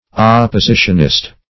Search Result for " oppositionist" : The Collaborative International Dictionary of English v.0.48: Oppositionist \Op`po*si"tion*ist\, n. One who belongs to the opposition party.